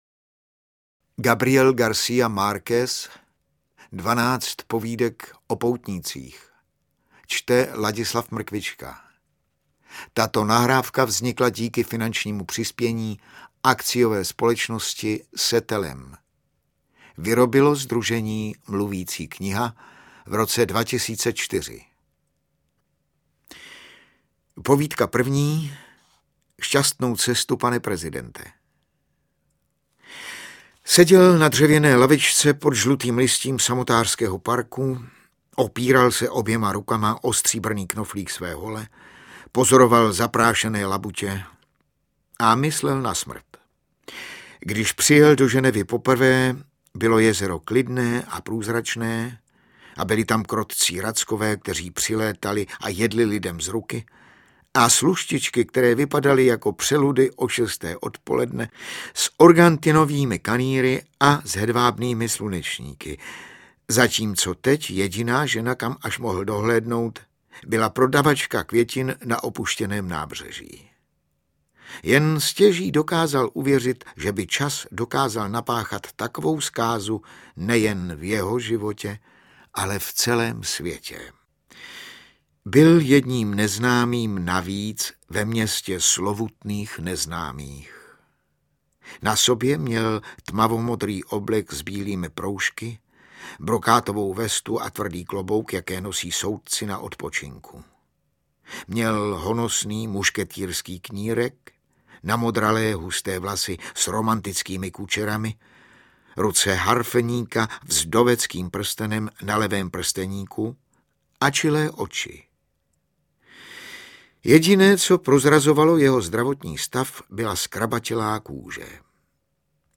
Mluvící kniha z.s.
Čte: Ladislav Mrkvička